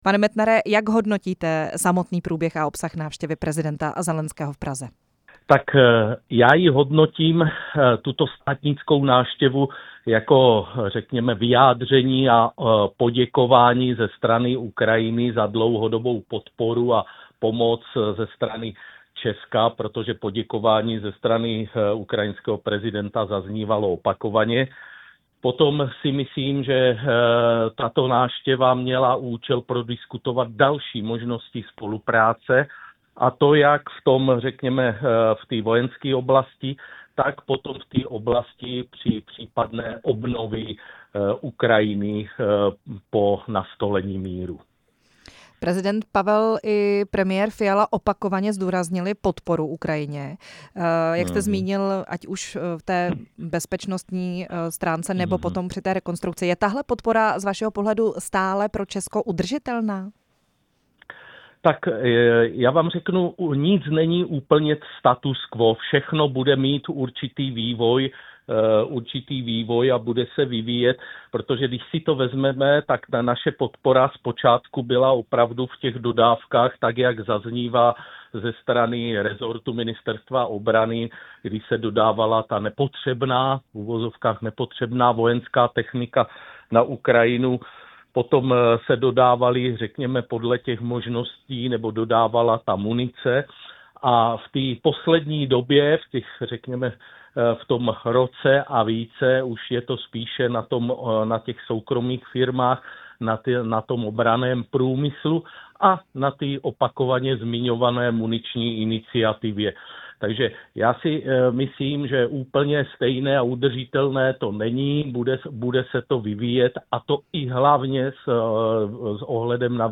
Rozhovor s exministrem obrany Lubomírem Metnarem